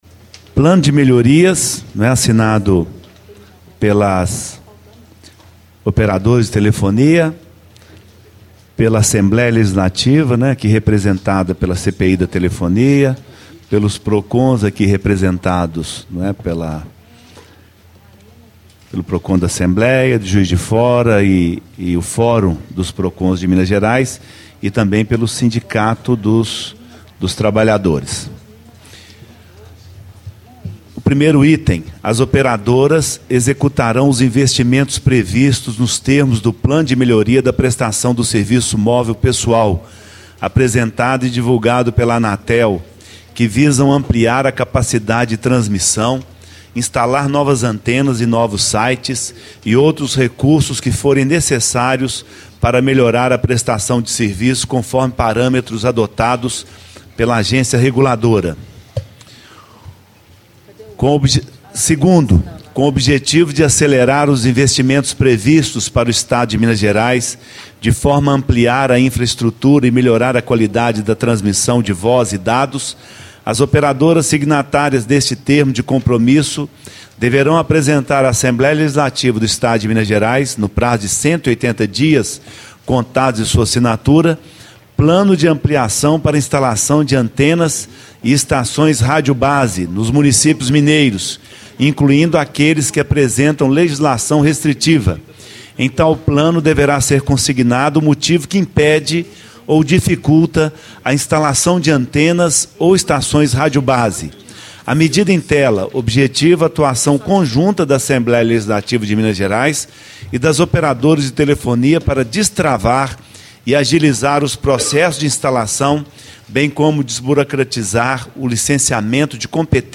Leitura do Termo de Melhorias proposto no Relatório Final - Deputado João Leite, PSDB - Relator